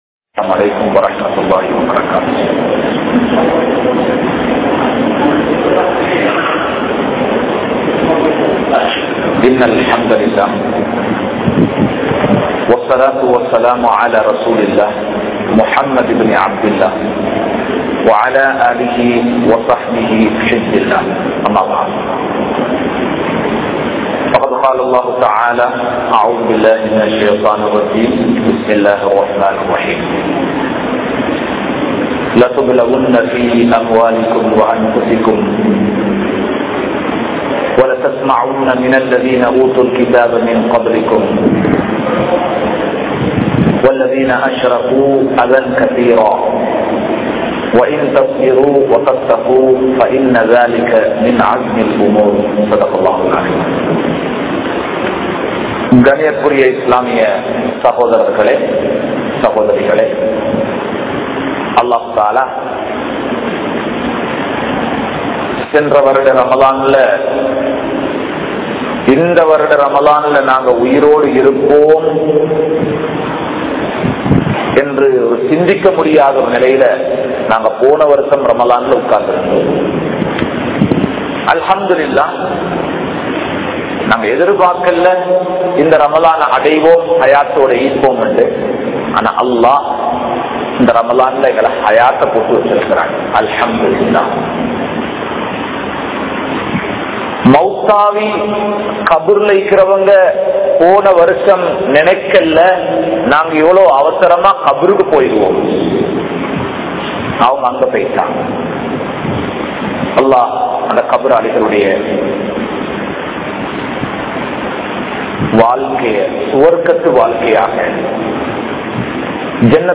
Maanathai Paathuhaarungal | Audio Bayans | All Ceylon Muslim Youth Community | Addalaichenai
Grand Jumua Masjitth